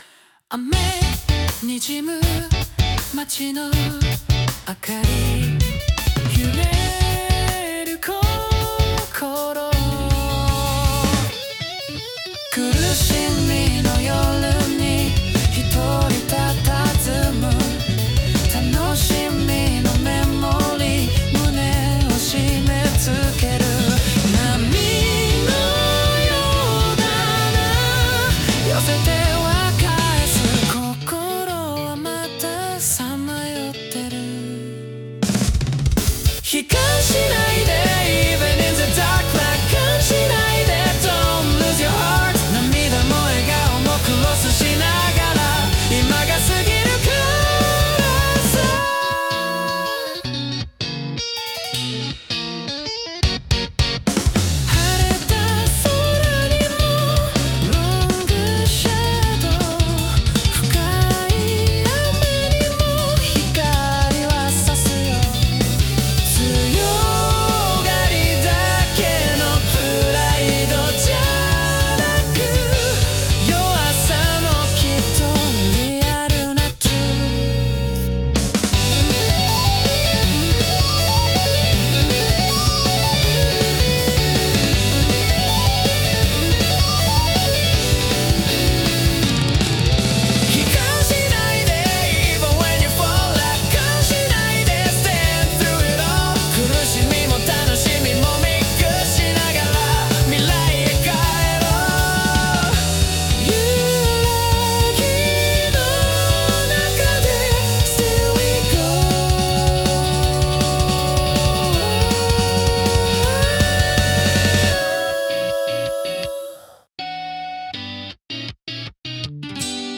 イメージ：プログレッシブ・ロック,アップテンポ,男性ボーカル